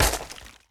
ground_hit.ogg